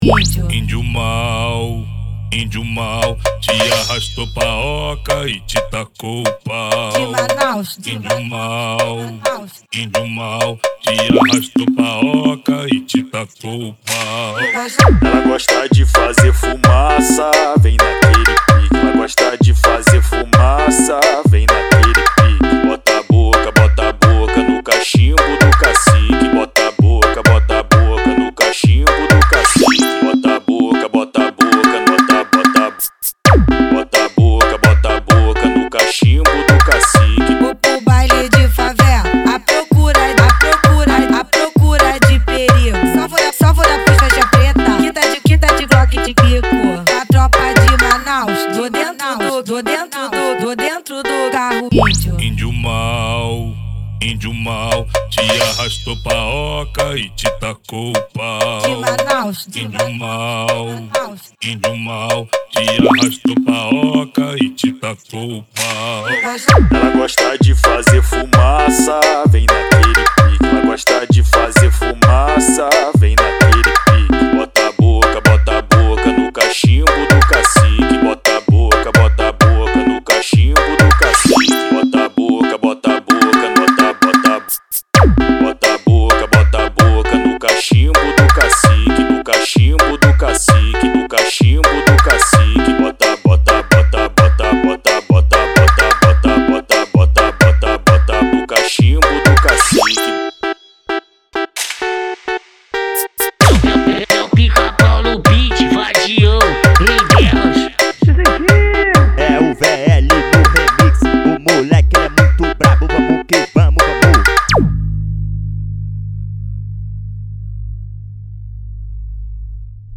EstiloArrochadeira